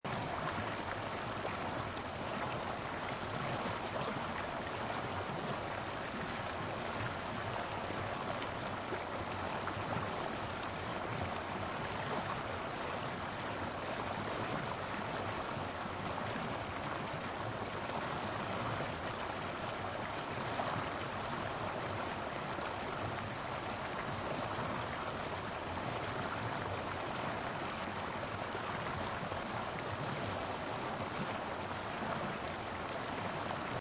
waterfall.mp3